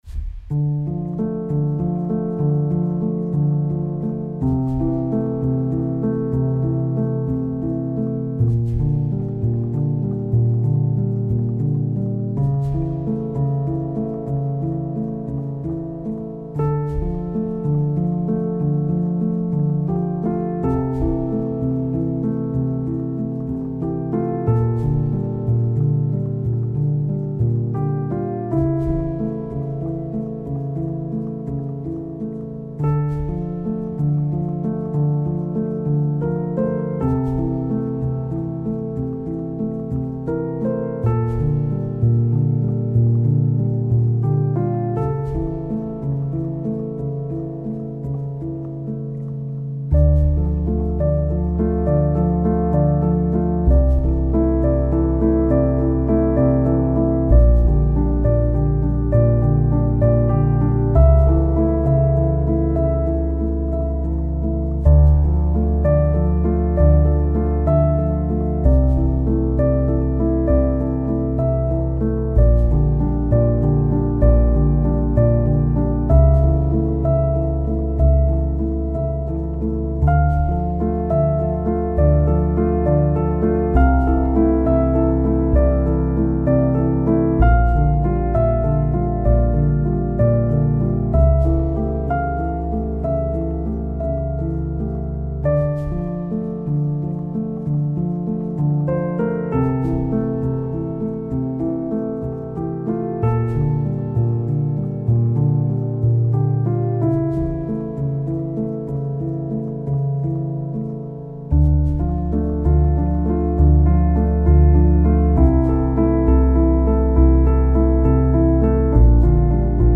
موسیقی بی کلام آرامش بخش الهام‌بخش پیانو عصر جدید